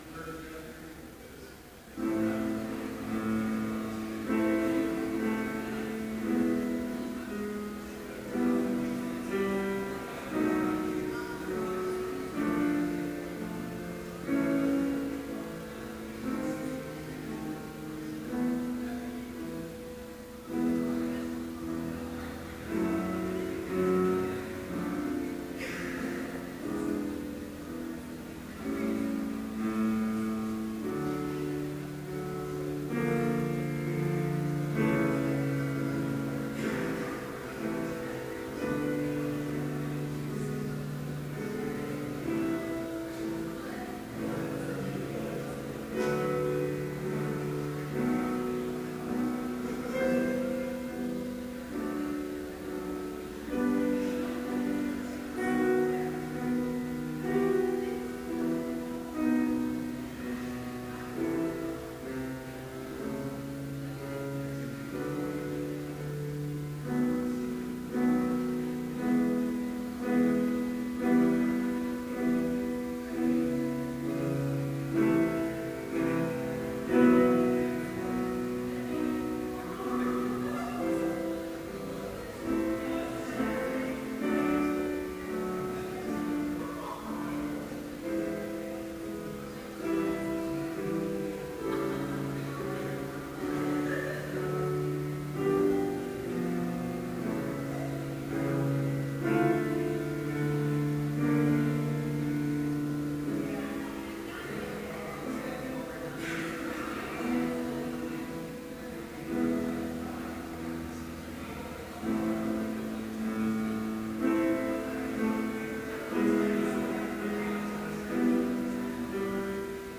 Complete service audio for Chapel - March 1, 2013
Order of Service Prelude Hymn 452, vv. 1-3, Out of the Depths I Cry Reading: Luke 13:22-29 Homily Prayer Hymn 452, vv. 4 & 5, And though it tarry… Benediction Postlude